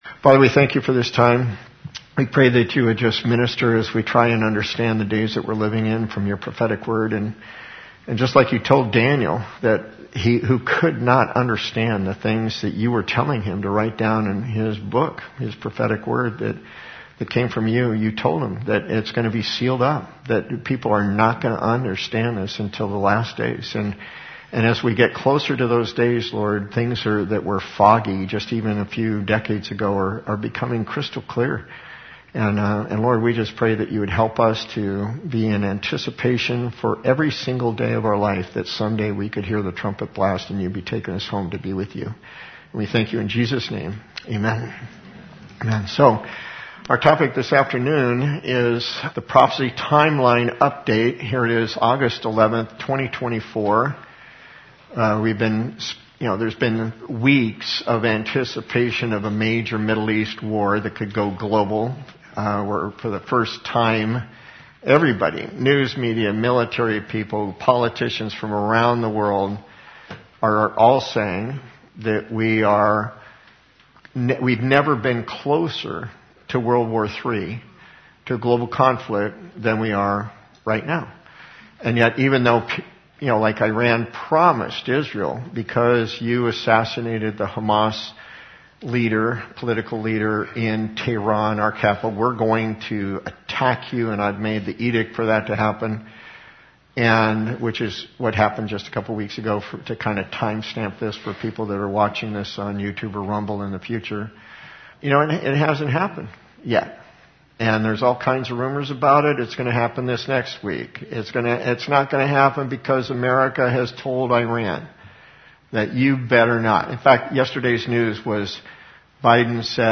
The Audio Version includes the Question and Answer session at the end.